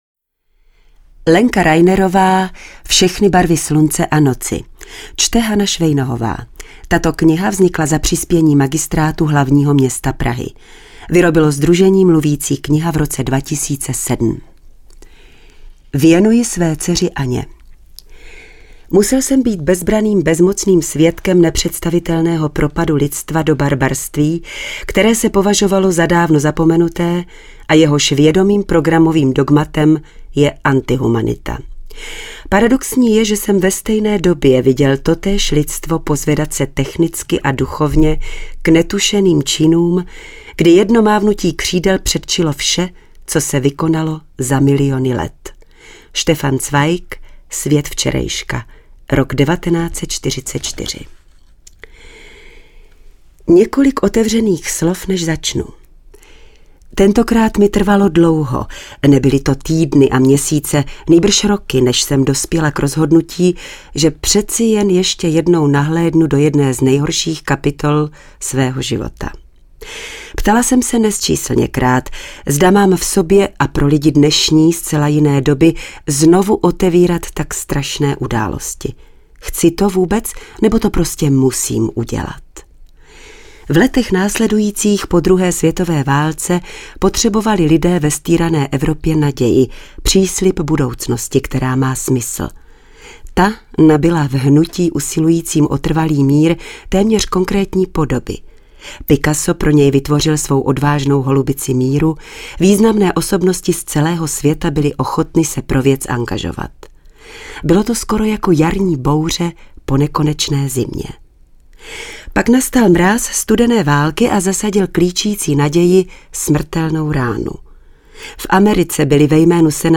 Čte: